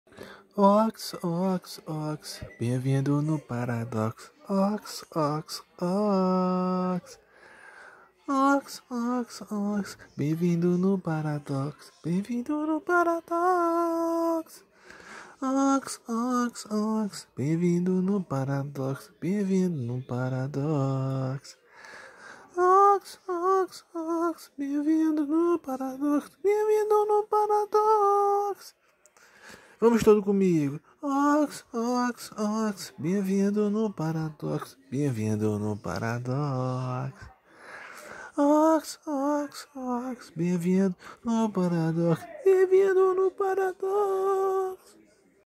2024-12-16 22:10:36 Gênero: MPB Views